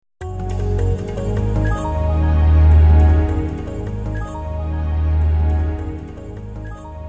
Sonido con Eco
Con Eco.mp3